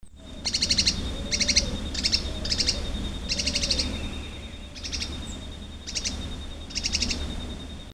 Spix´s Spinetail (Synallaxis spixi)
Life Stage: Adult
Location or protected area: Delta del Paraná
Condition: Wild
Certainty: Observed, Recorded vocal